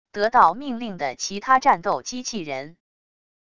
得到命令的其他战斗机器人wav音频